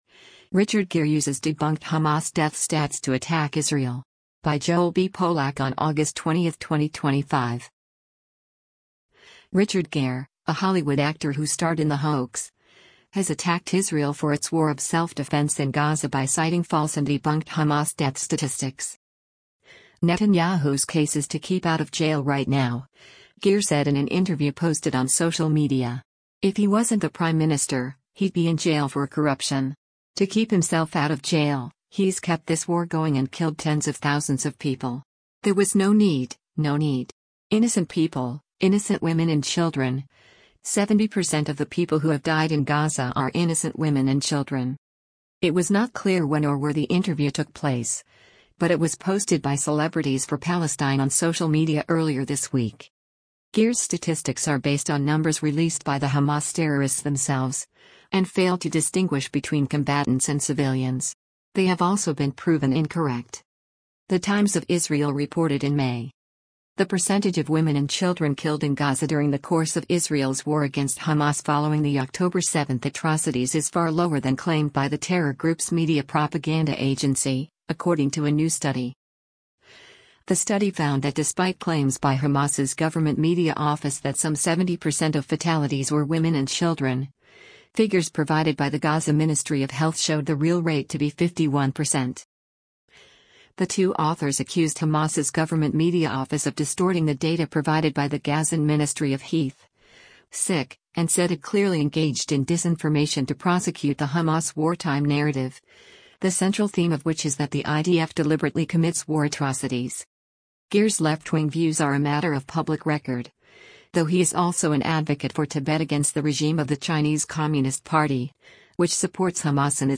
“Netanyahu’s case is to keep out of jail right now,” Gere said in an interview posted on social media.